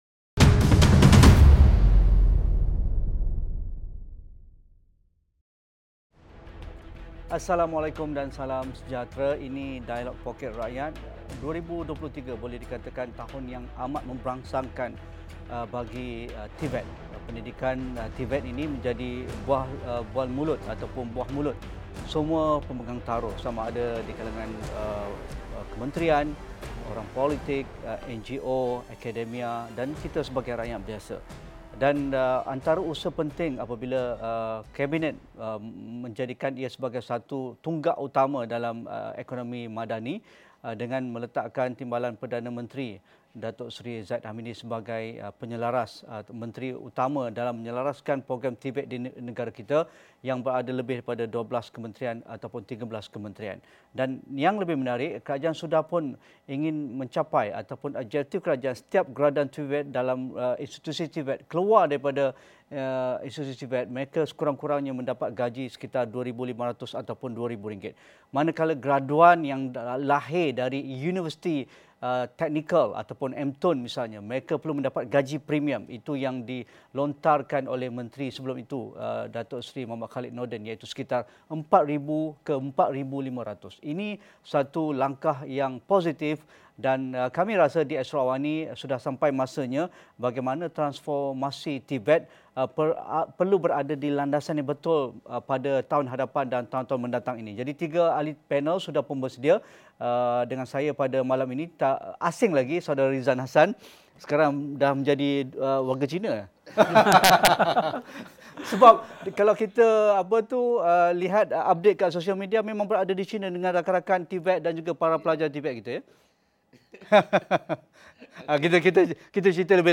Ikuti diskusi dan analisis sejauhmana transformasi TVET dapat memperkasa dan memacu ekonomi serta memberi pengiktirafan tinggi dalam trend guna tenaga negara dalam Dialog Poket Rakyat jam 8:30 malam ini.